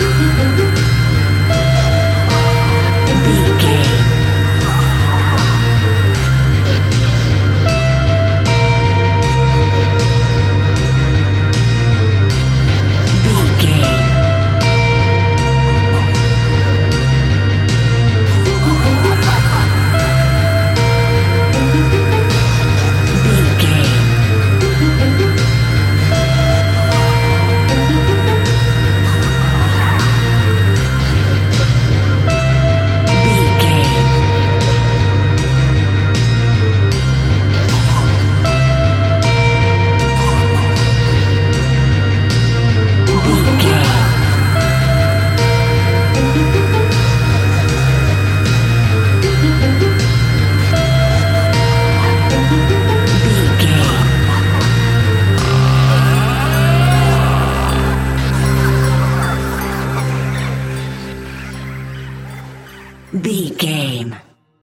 Aeolian/Minor
DOES THIS CLIP CONTAINS LYRICS OR HUMAN VOICE?
tension
ominous
haunting
eerie
strings
synthesiser
percussion
electric guitar
drums
electric organ
harp
horror music